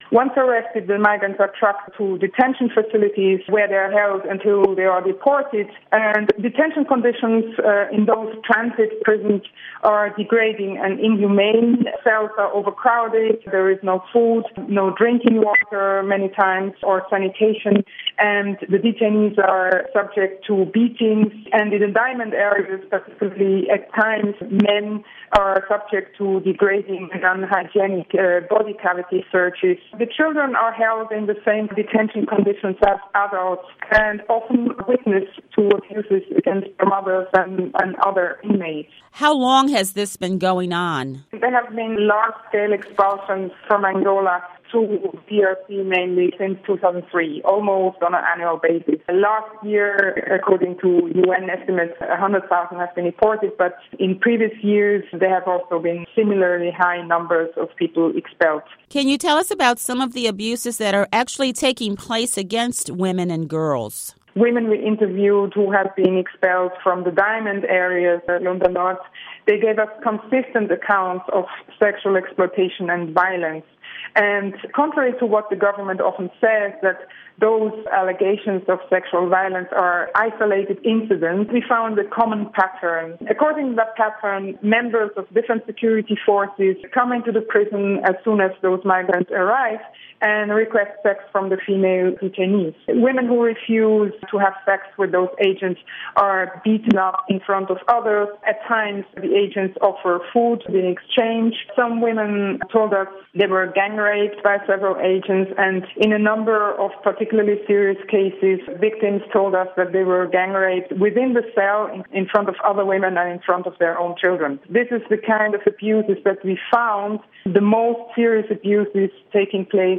interview on Congolese migrants